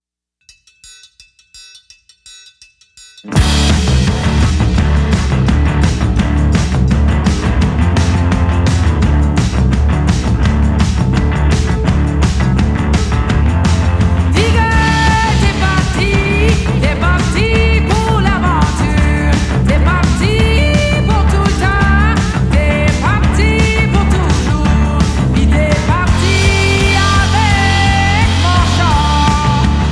Жанр: Фолк-рок / Альтернатива